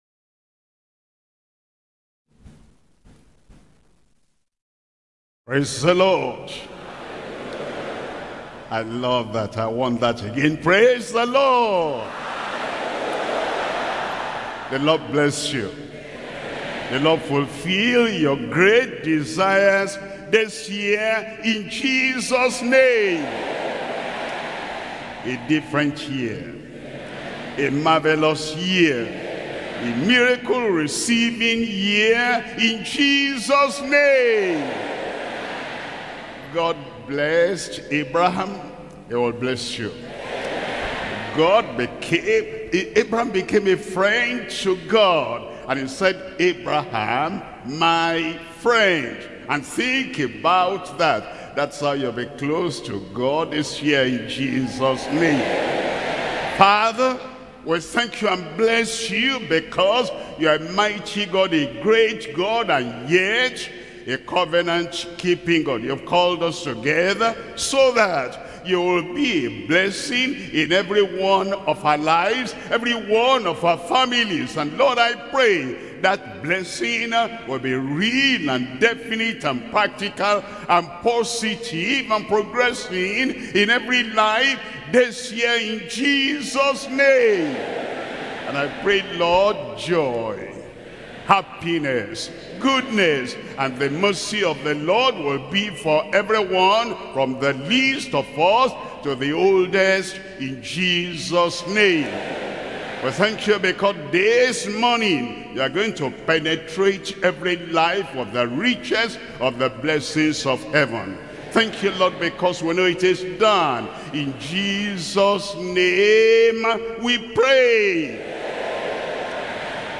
SERMONS – Deeper Christian Life Ministry Australia